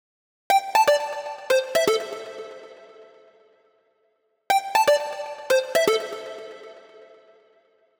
23 MonoSynth PT1.wav